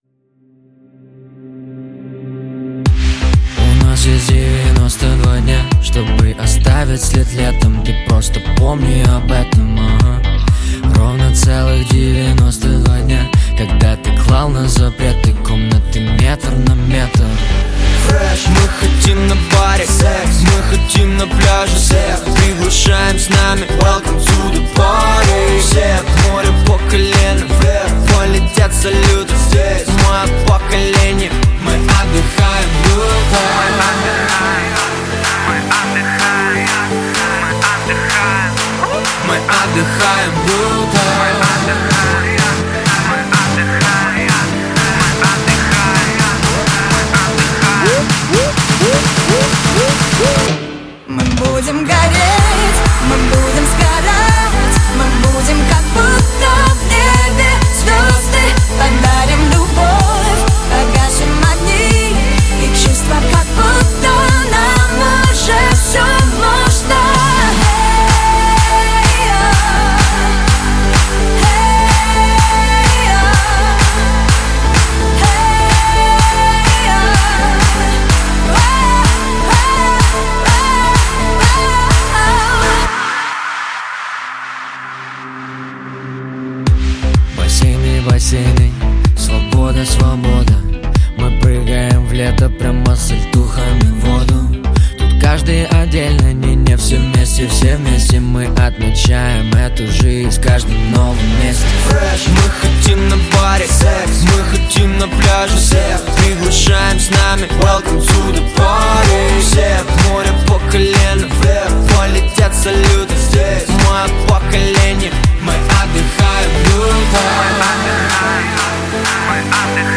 Жанр: Жанры / Электроника